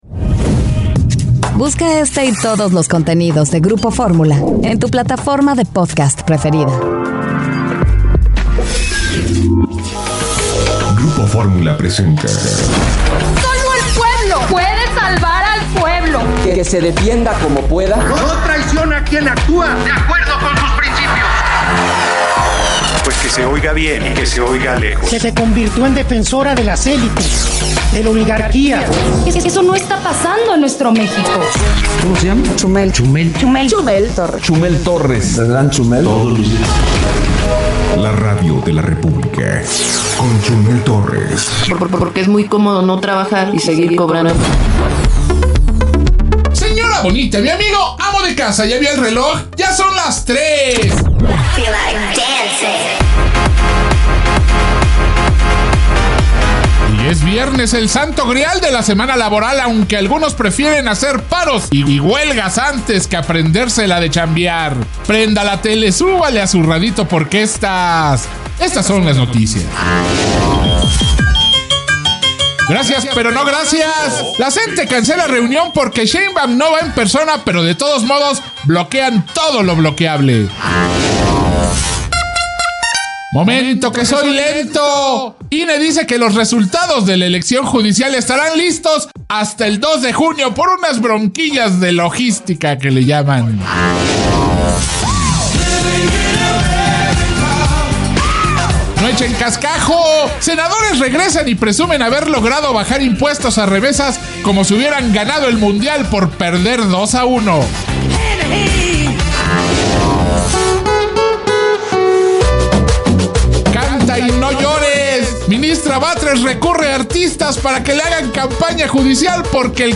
Aquí está la Radio de la República, el peor noticiero con su tuitero favorito Chumel Torres.